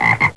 frog_att.wav